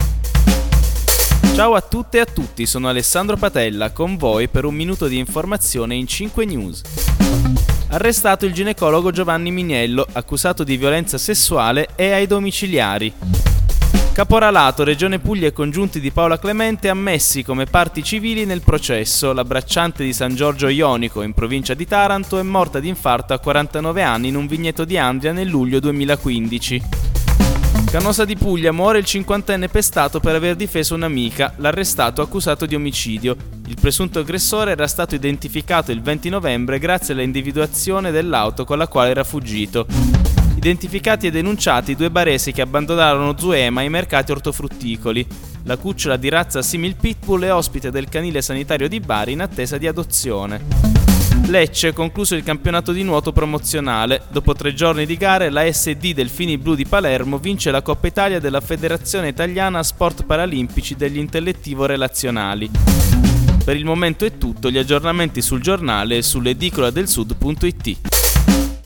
Giornale radio alle ore 19